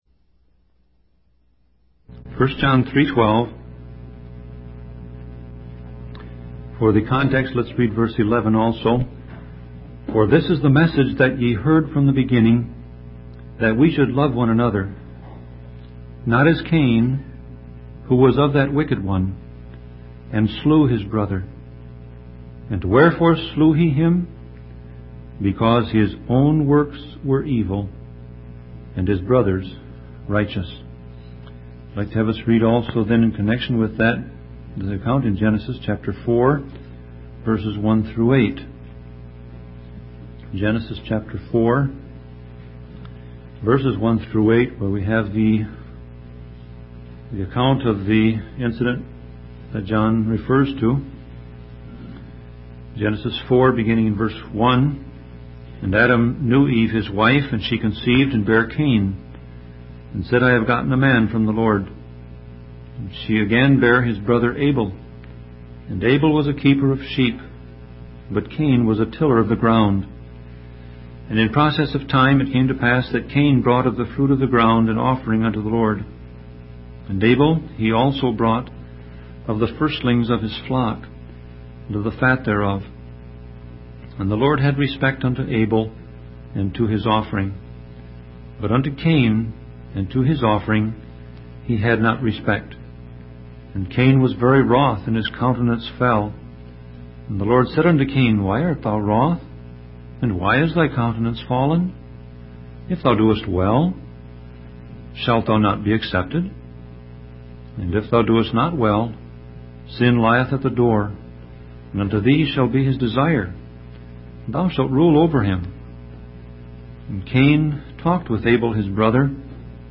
Sermon Audio Passage: 1 John 3:12 Service Type